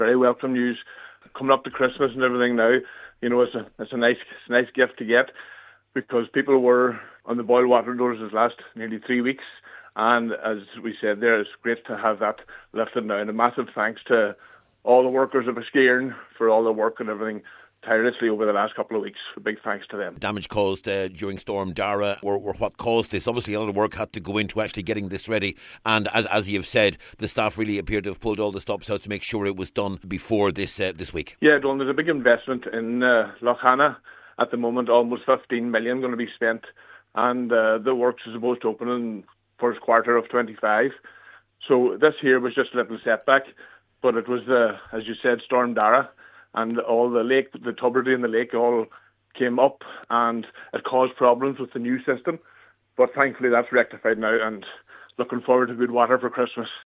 Cllr Brian Carr says the news will come as a relief to residents in the area with Christmas right around the corner………….